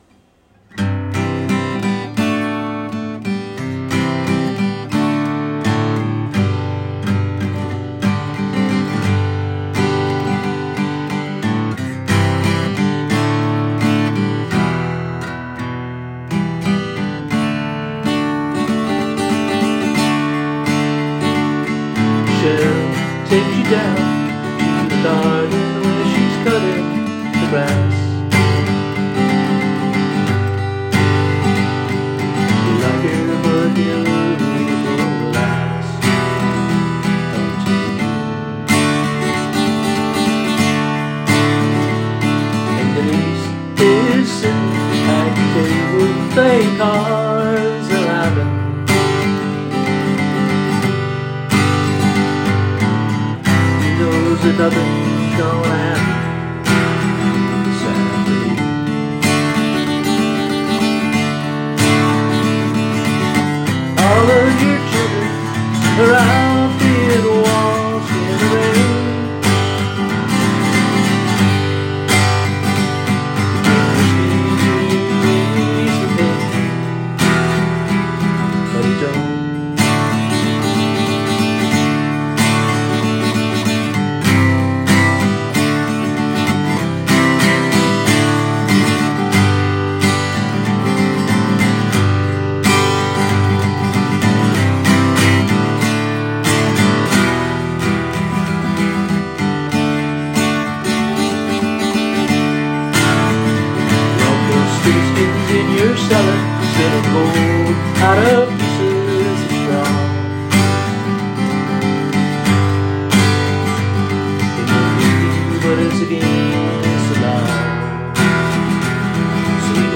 I play acoustic guitar playing some of my I own songs with a few Irish ballads and pop (sixties) mixed in.